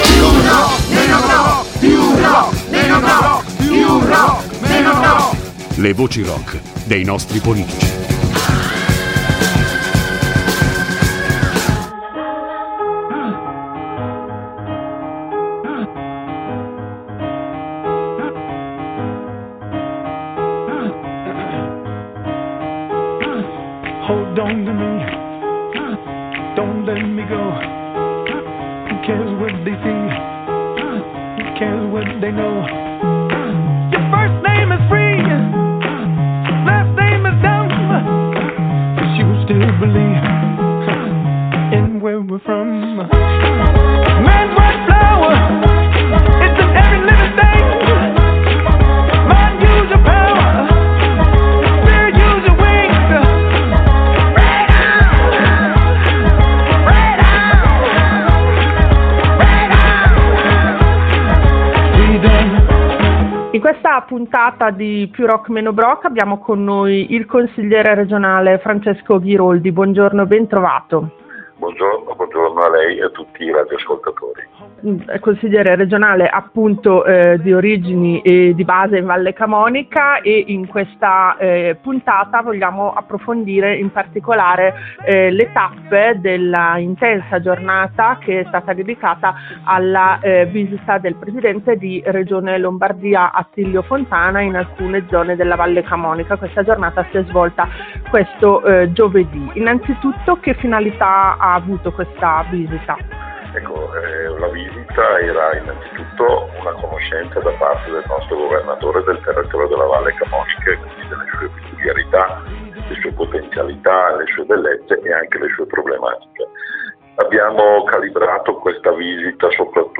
Più rock meno broc Intervista a Francesco Ghiroldi sulla visita di Attilio Fontana in Vallecamonica
Francesco Ghiroldi, consigliere regionale